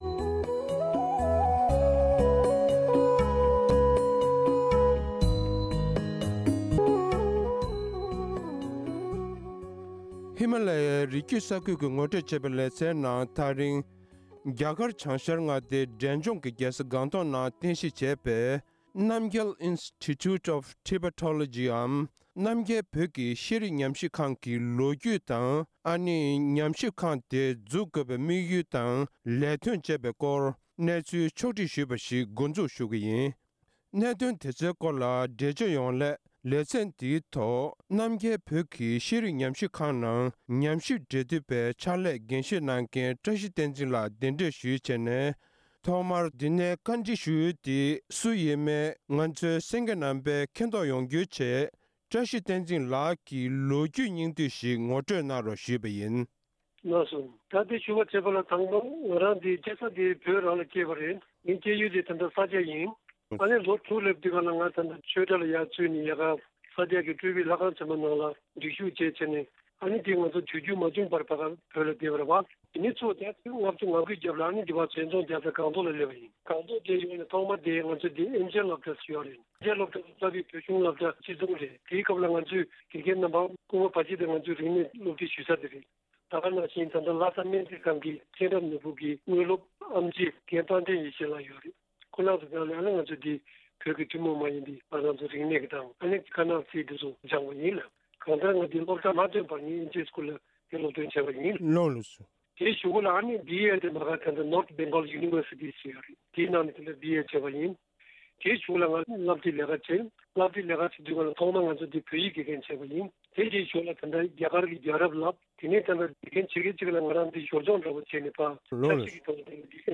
༄༅།    །ཐེངས་འདིའི་ཧི་མ་ལ་ཡའི་རི་བརྒྱུད་ས་ཁུལ་གྱི་ངོ་སྤྲོད་ཅེས་པའི་ལེ་ཚན་ནང་།    རྒྱ་གར་བྱང་ཤར་འབྲས་ལྗོངས་མངའ་སྡེའི་རྒྱལ་ས་སྒང་ཏོག་ནང་རྟེན་གཞི་བྱེད་པའི་རྣམ་རྒྱལ་བོད་ཀྱི་ཤེས་རིག་ཉམས་ཞིབ་ཁང་གི་སྐོར་ངོ་སྤྲོད་གླེང་མོལ་ཞུས་པ་ཞིག་གསན་རོགས་གནང་།